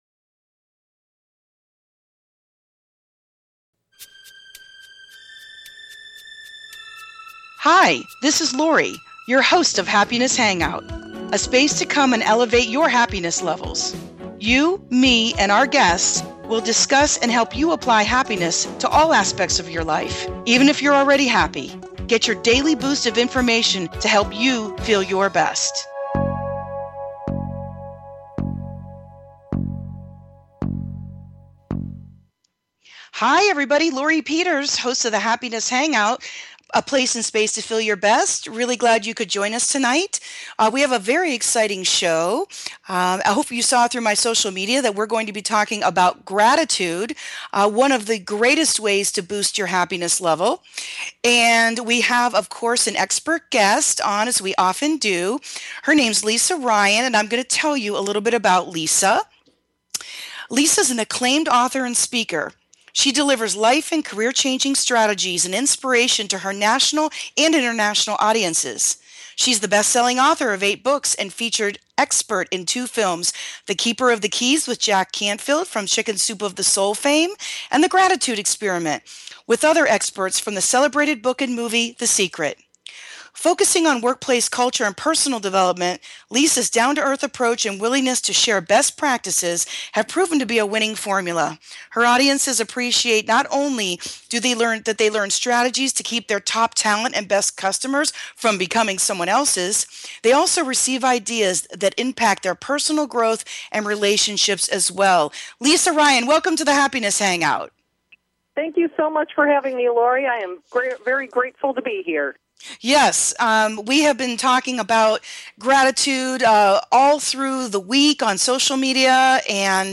My guests are relationships experts, life coaches, therapists and much more. We discuss all aspects of how to create and keep a healthy relationship.